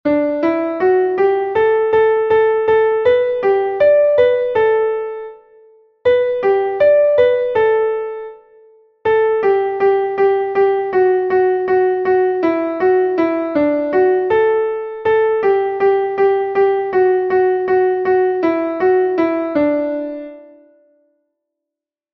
Melodie: Volksweise